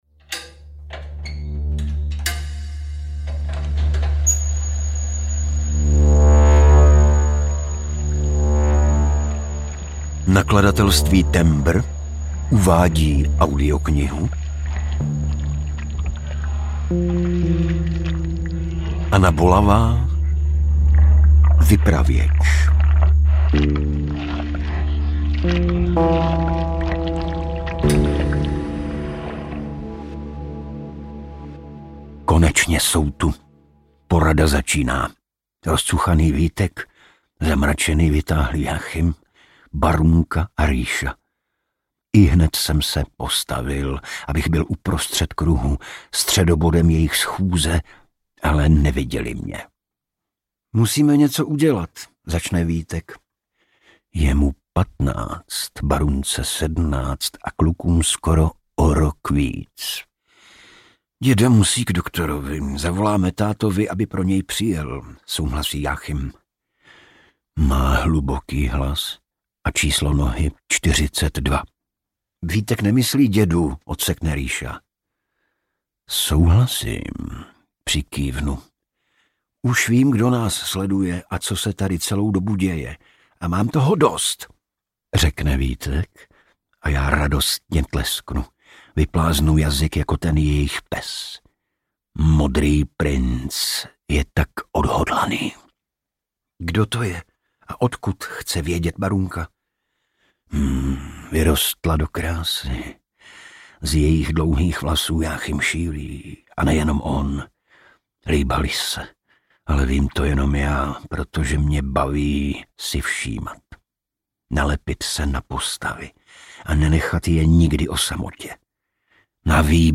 Vypravěč audiokniha
Ukázka z knihy